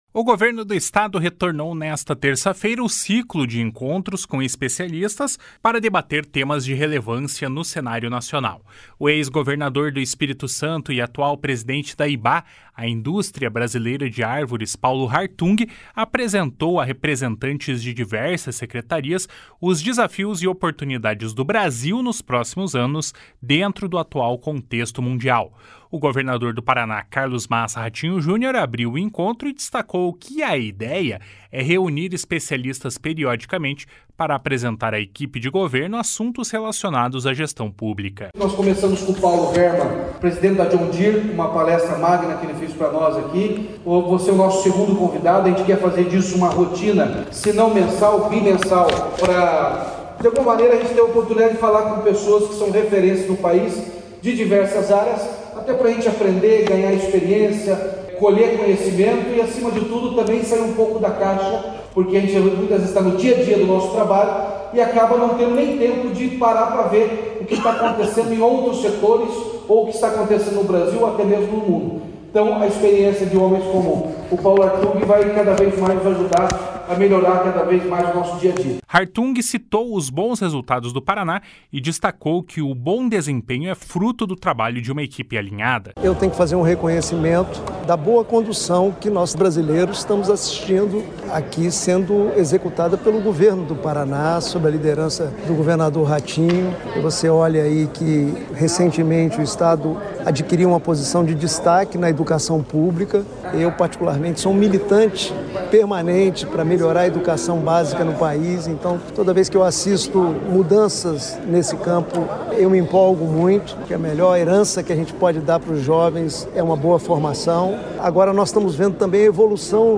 PALESTRA DE PAULO HARTUNG.mp3